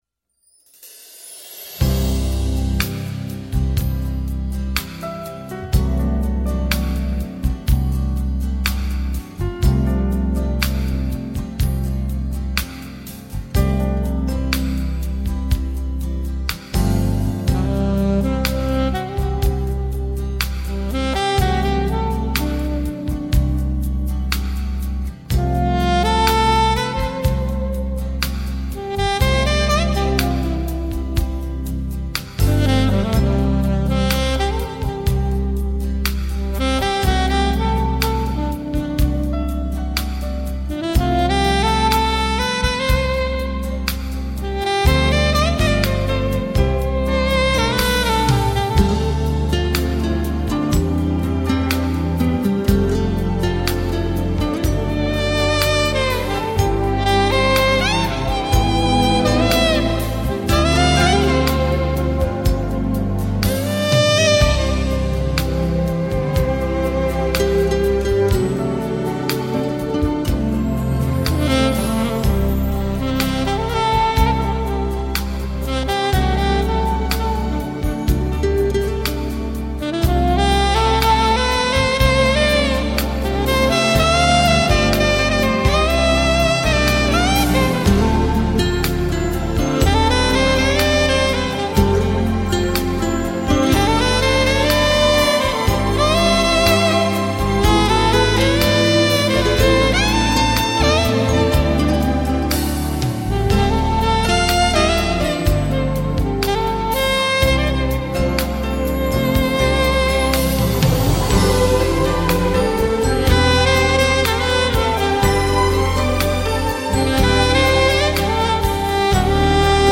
它横跨爵士、跨界、成人抒情、流行演奏等多个领域，是SmoothJazz音乐界的代名词。